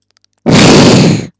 fireball.mp3